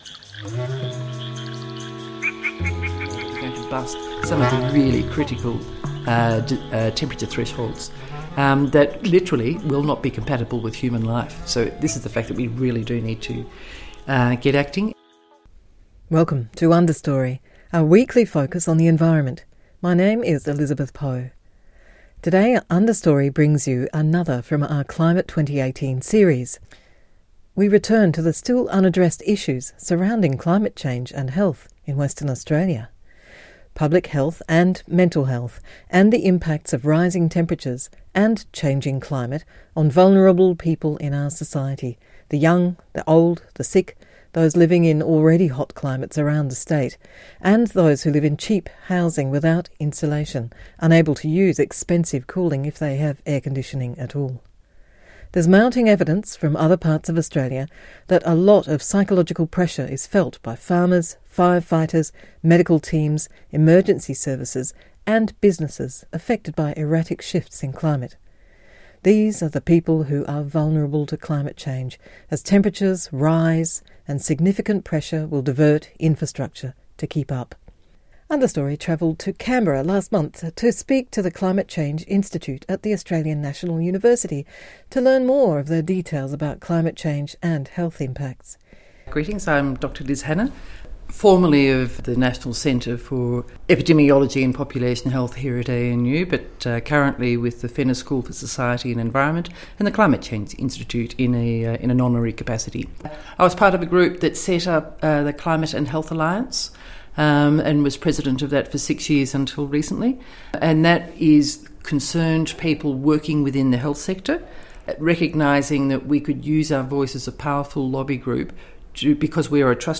Revisiting the radio vault from 2013, speaking to then-Greens leader Christine Milne, we discover successive Western Australian Health Departments have not engaged in any wide community discussion about the climate change challenges lying ahead for our public health.